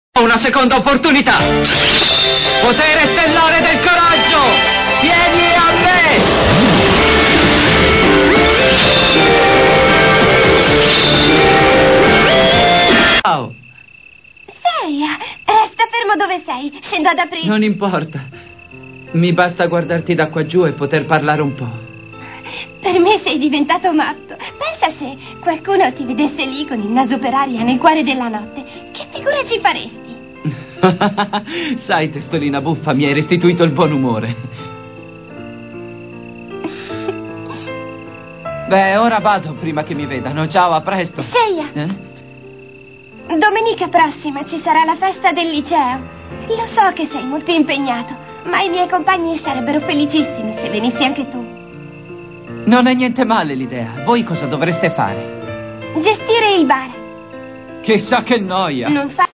dal cartone animato "Petali di stelle per Sailor Moon", in cui doppia Seiya.